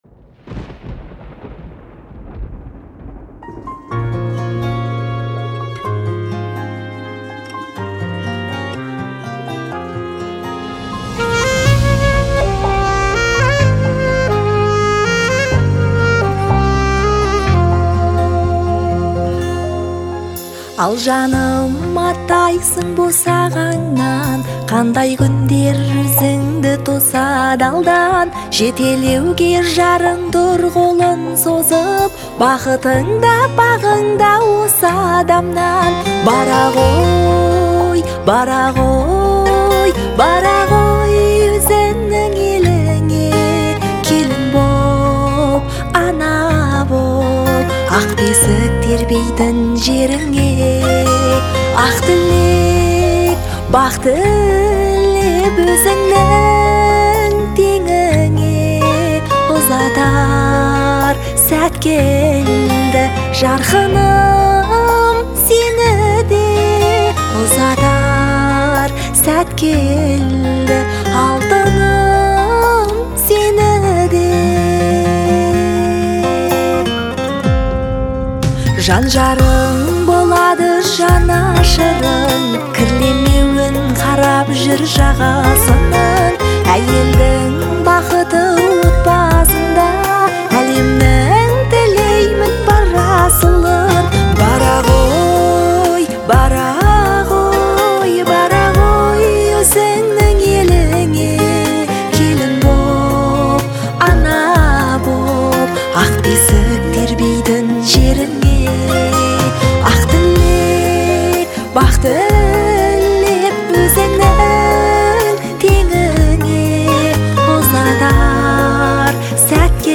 это яркий образец казахской народной музыки